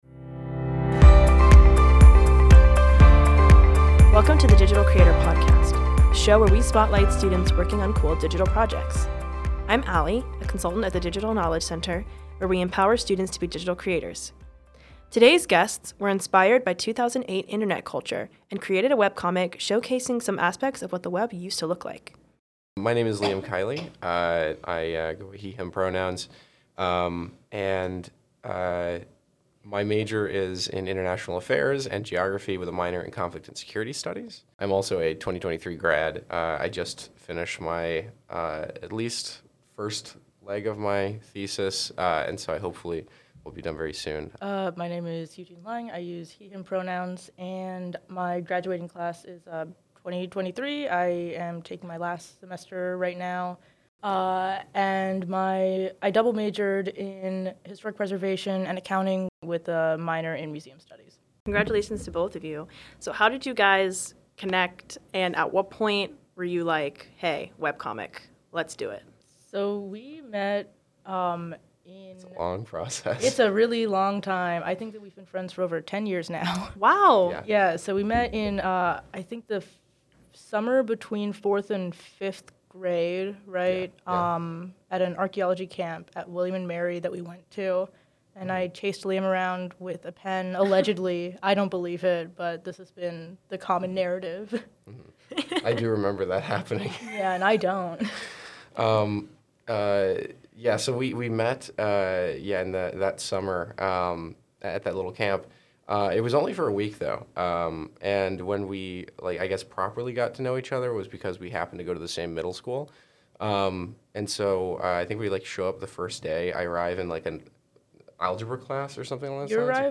two-gamers-on-a-podcast-losing-ctrl-and-archiving-2008-internet-culture.mp3